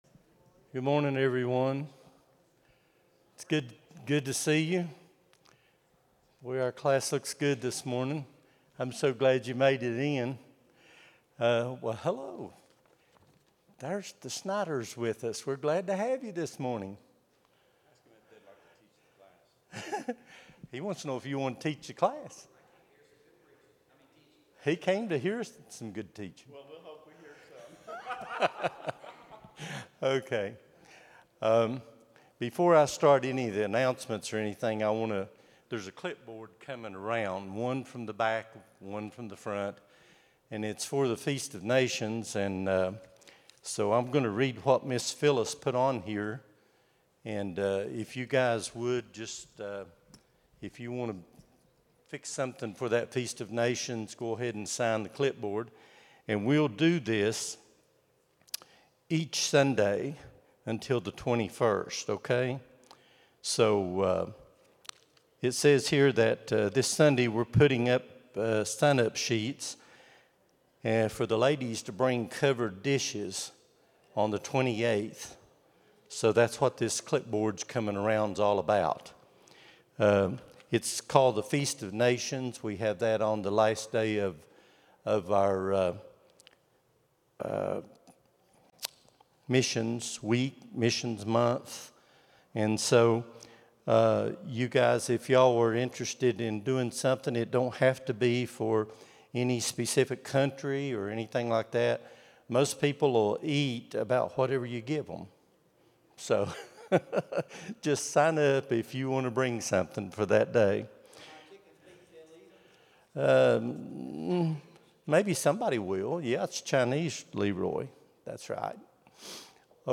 Sunday School lesson
at Buffalo Ridge Baptist Church in Gray, Tn.